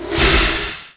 1 channel
hitMagic.wav